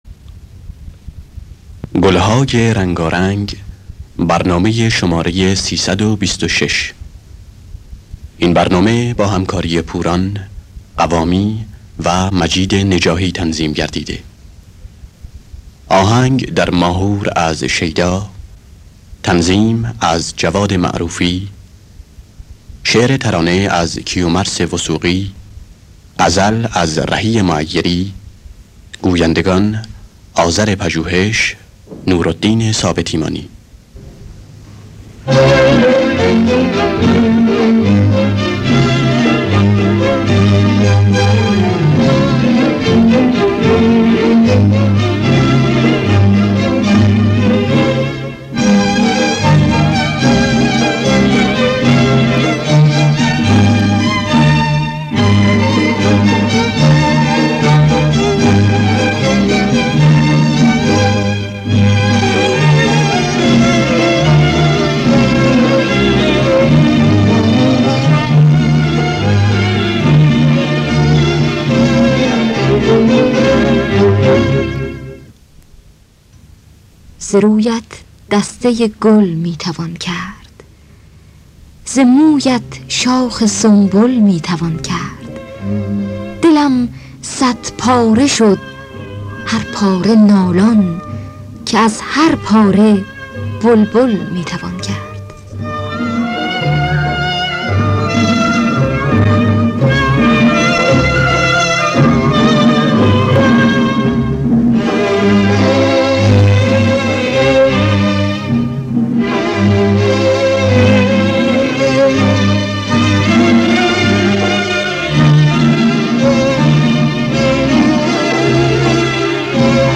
گلهای رنگارنگ ۳۲۶ - ماهور
خوانندگان: پوران حسین قوامی